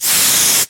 airride.wav